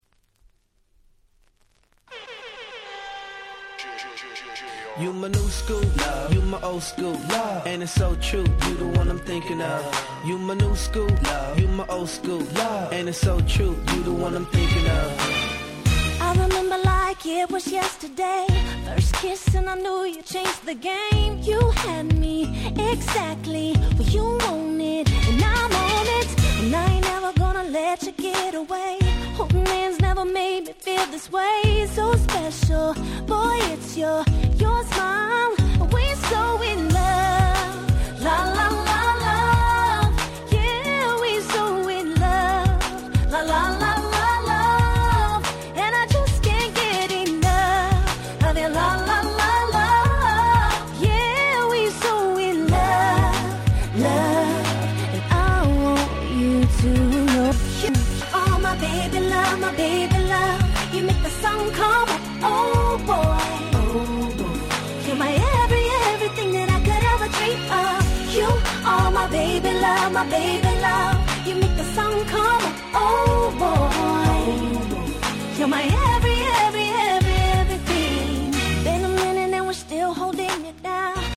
07' Smash Hit R&B♪